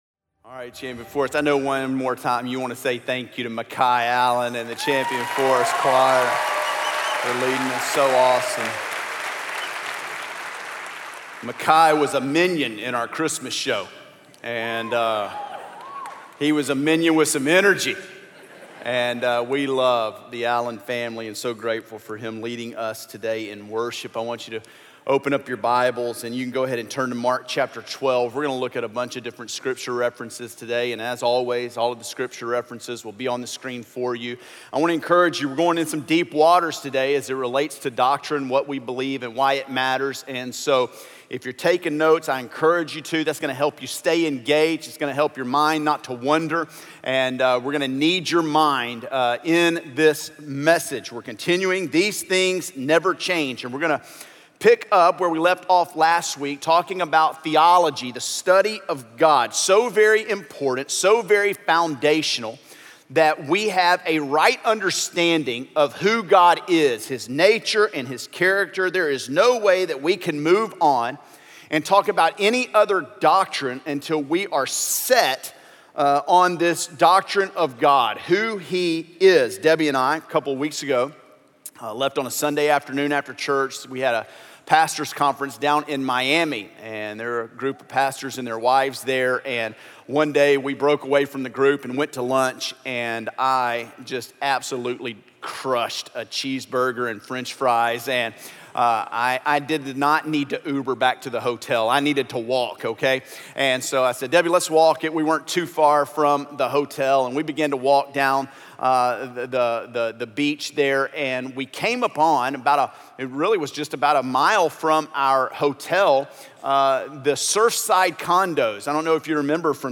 ENG-SERMON_1.mp3